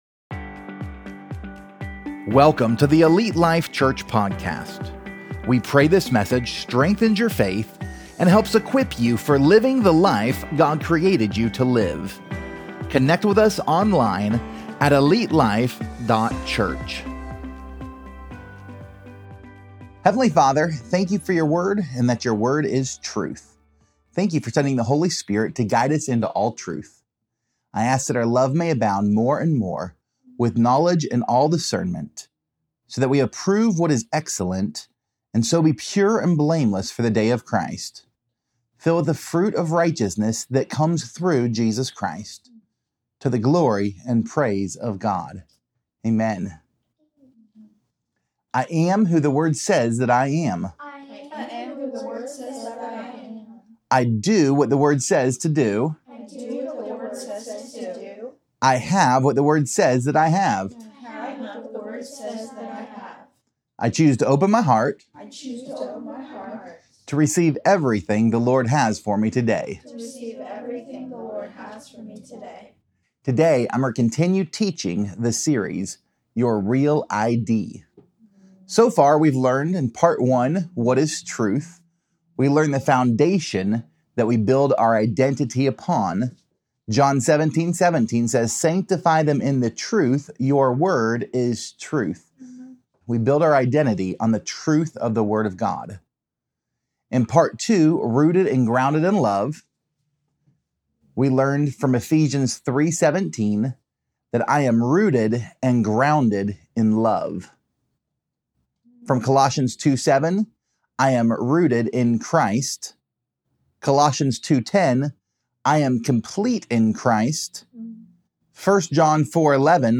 Pt 05: I'm a Saint! | Your REAL ID Sermon Series